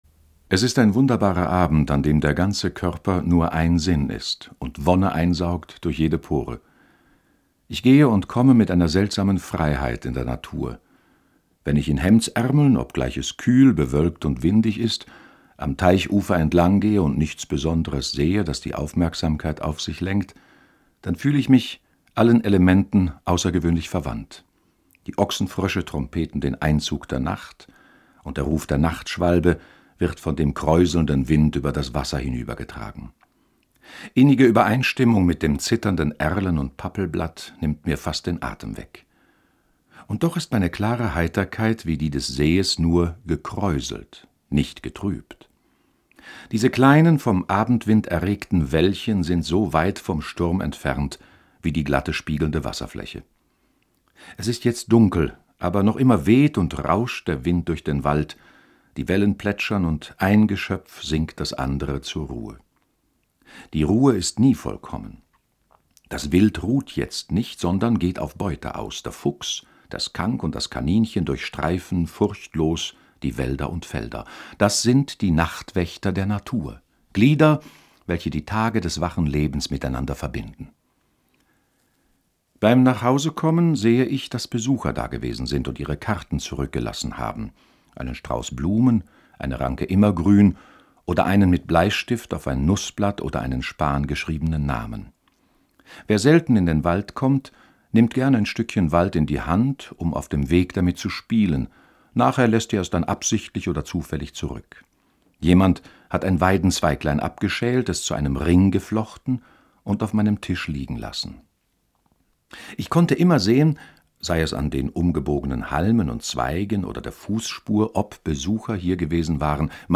In der heutigen Lesung preist Thoreau die Einsamkeit. Sie ermöglicht ungestörte Naturbeobachtungen. Gesellschaft vermisst Thoreau kaum.